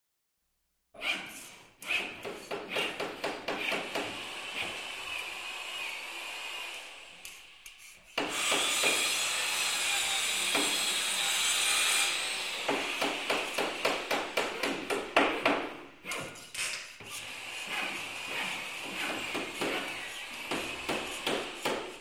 Здесь вы найдете шумы инструментов, техники и обстановки строительной площадки.
Звуки ремонта и стройки: Шум из квартиры